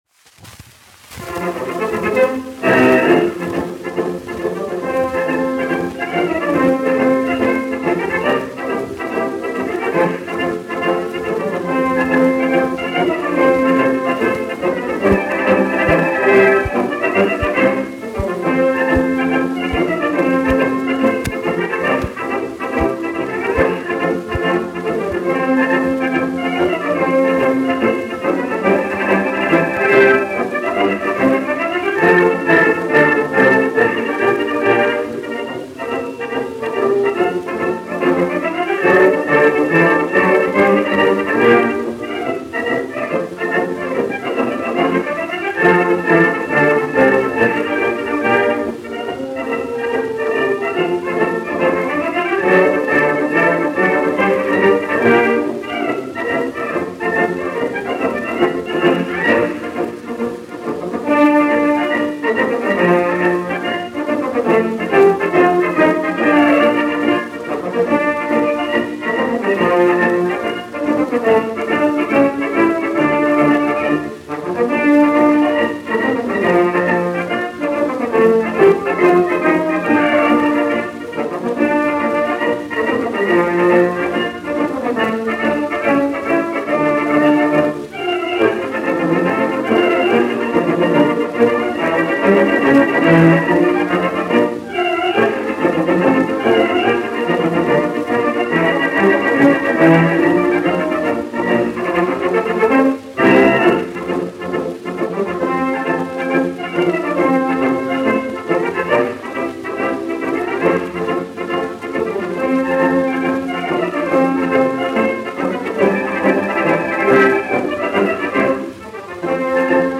1 skpl. : analogs, 78 apgr/min, mono ; 25 cm
Marši
Pūtēju orķestra mūzika
Skaņuplate
Latvijas vēsturiskie šellaka skaņuplašu ieraksti (Kolekcija)